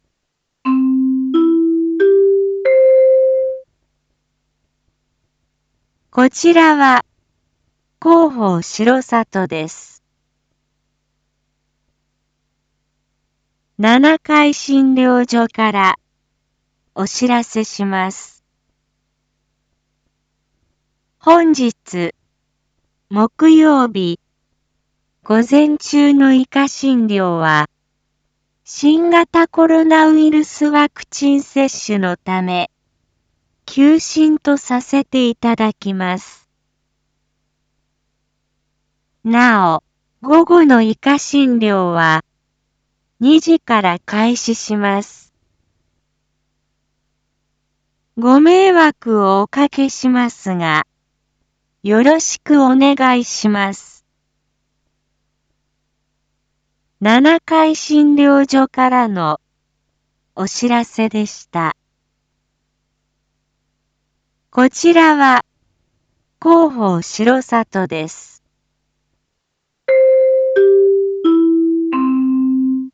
一般放送情報
Back Home 一般放送情報 音声放送 再生 一般放送情報 登録日時：2022-07-07 07:01:14 タイトル：R4.7.7 7時放送分 インフォメーション：こちらは広報しろさとです。